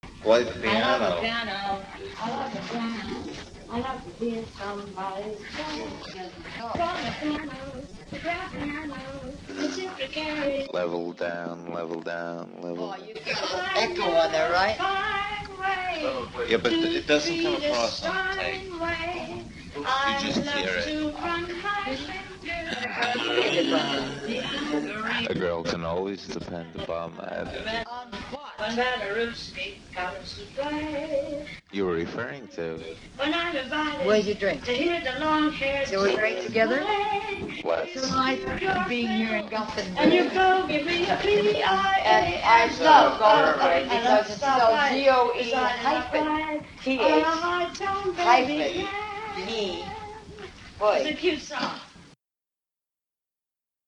Impromptu “jam session” (what’s known to survive):